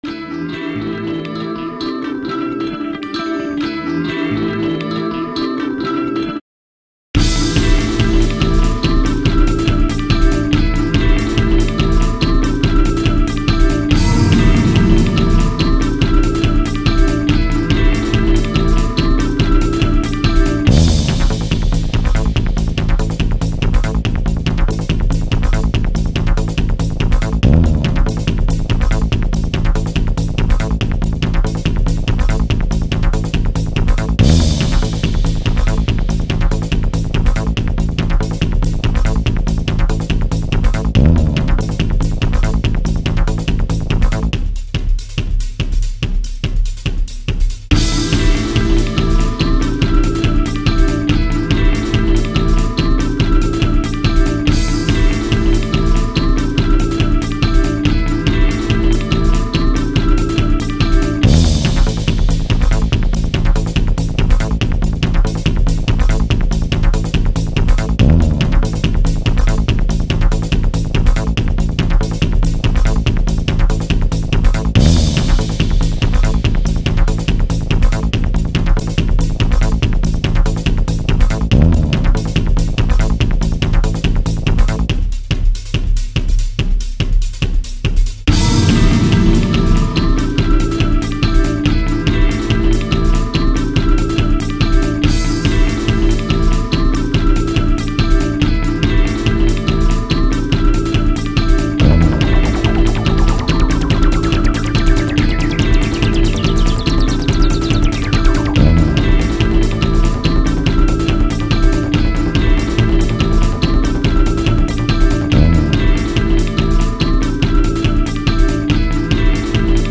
単調だが疾走感だけは出たかなぁ、と(笑)。